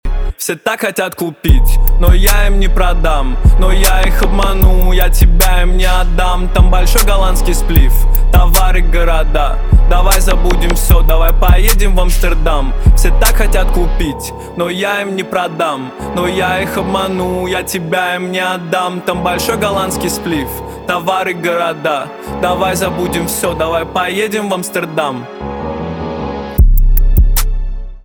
русский рэп
басы , пианино